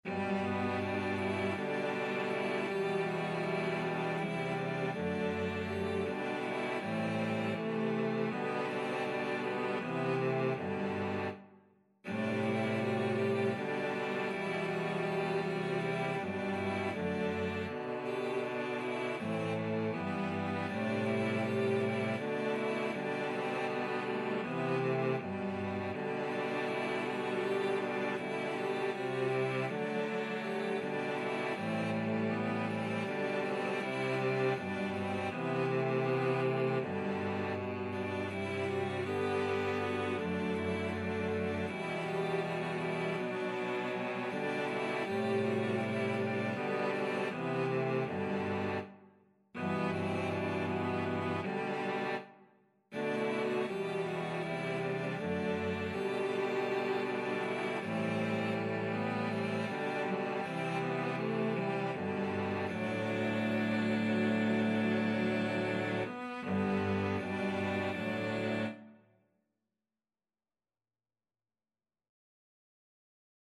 Traditional Music of unknown author.
G major (Sounding Pitch) (View more G major Music for Cello Quartet )
4/4 (View more 4/4 Music)
Cello Quartet  (View more Easy Cello Quartet Music)
Christian (View more Christian Cello Quartet Music)
salve_regina_4VLC.mp3